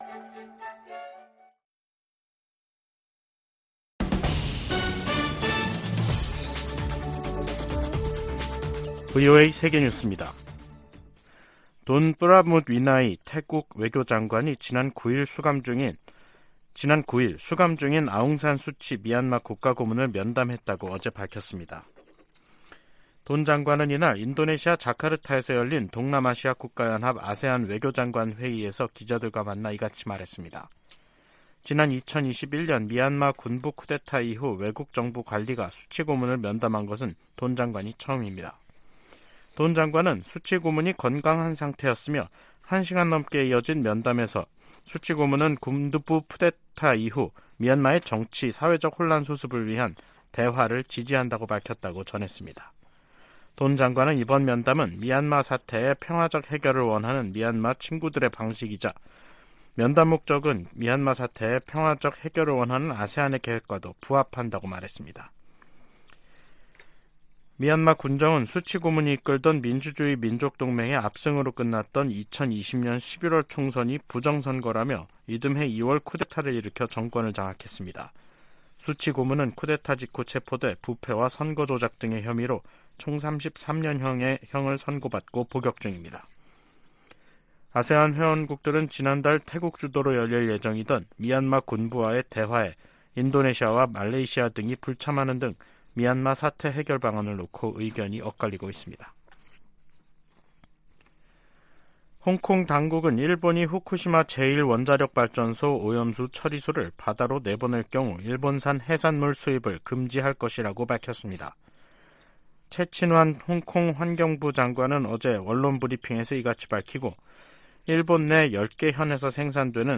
VOA 한국어 간판 뉴스 프로그램 '뉴스 투데이', 2023년 7월 13일 2부 방송입니다. 13일 북한 당국이 전날(12일) 발사한 탄도미사일이 고체연료 대륙간탄도미사일(ICBM) 화성-18호라고 밝혔습니다. 미국 정부와 의회, 유엔과 나토 사무총장, 한일 정상, 미한일 합참의장이 북한 정권의 장거리탄도미사일 발사를 강력히 규탄하며 대화에 나설 것을 촉구했습니다. 북한 주민들에게 자유와 진실의 목소리를 전해야 한다고 미국 의원들이 강조했습니다.